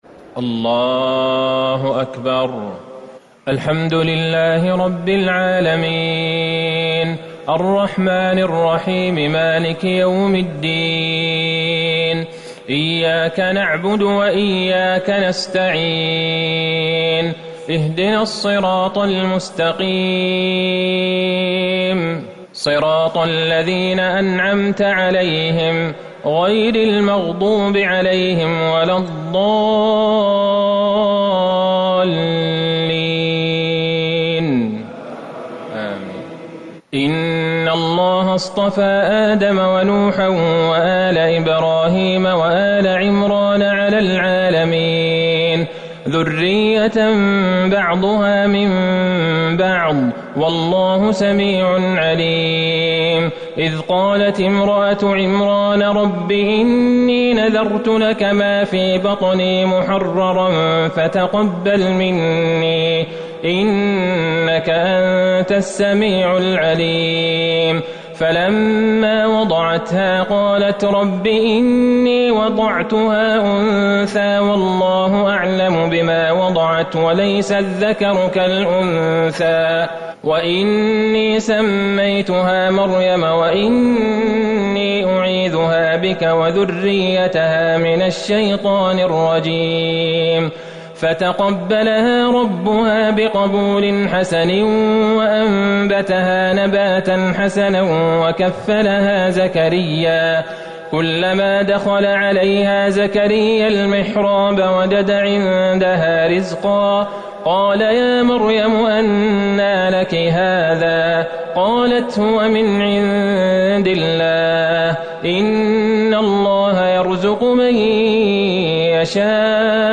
تهجد ٢٣ رمضان ١٤٤٠ من سورة ال عمران ٣٣ - ٩٢ > تراويح الحرم النبوي عام 1440 🕌 > التراويح - تلاوات الحرمين